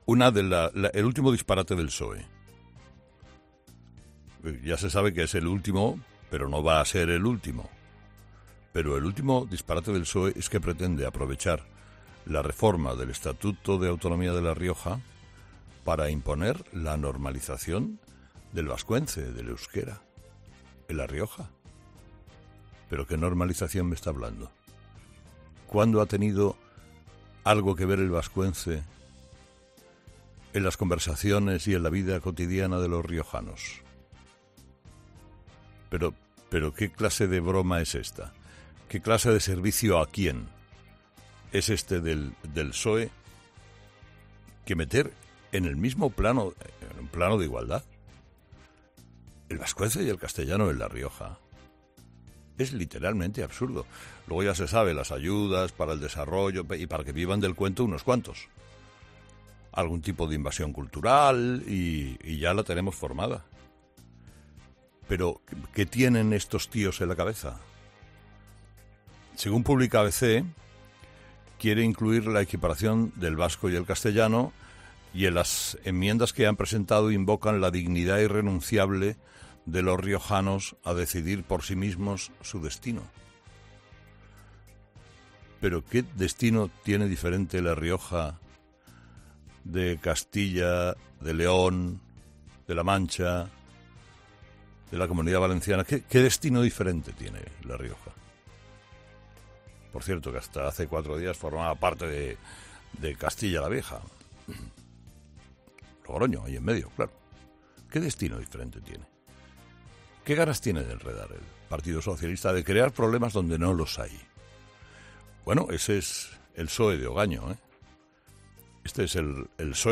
El anuncio no ha pasado inadvertido para Carlos Herrera, que lo tilda de "absoluto disparate".